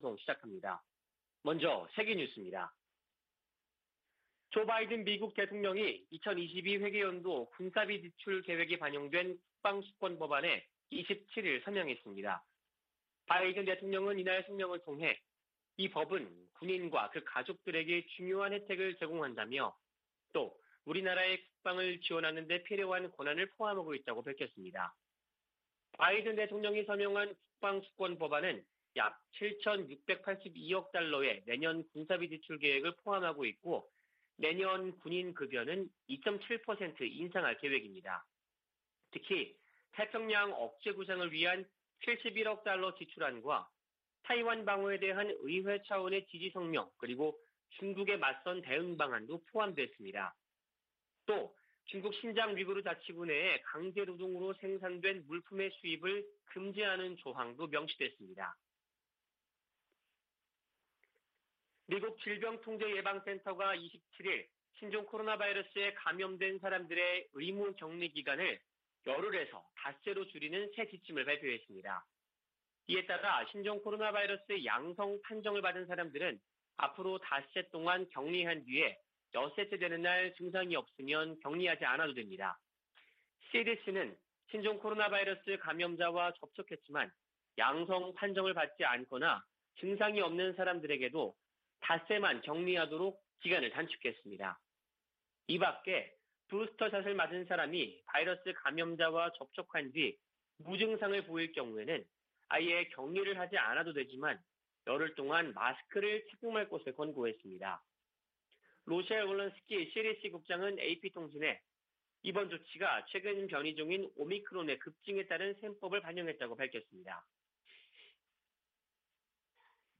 VOA 한국어 '출발 뉴스 쇼', 2021년 12월 29일 방송입니다. 북한이 27일 김정은 국무위원장 주재로 올 들어 네번째 노동당 전원회의를 개최했습니다. 조 바이든 미국 행정부는 출범 첫 해 외교를 강조하며 북한에 여러 차례 손을 내밀었지만 성과를 거두진 못했습니다.